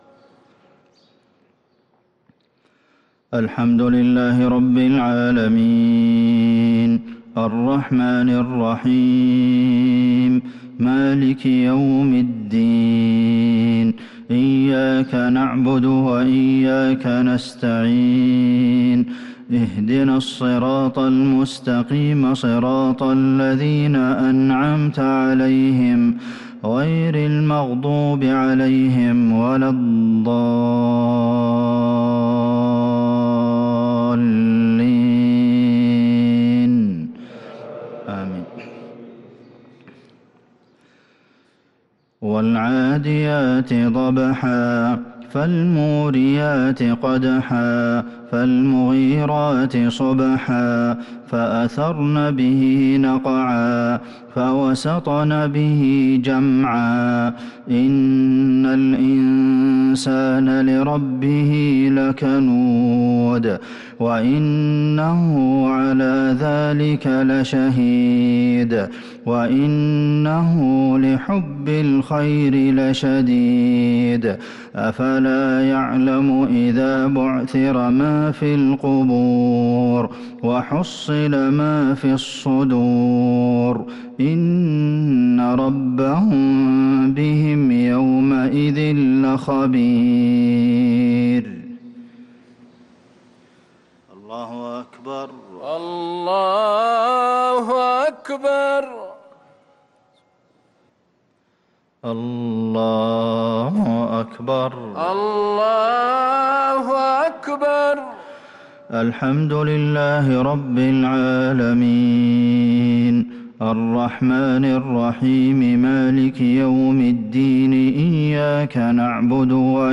مغرب الاثنين 4-8-1443هـ سورتي العاديات و الماعون | Maghrib prayer from Surah al-`Adiyat & al-Ma`un 7-3-2022 > 1443 🕌 > الفروض - تلاوات الحرمين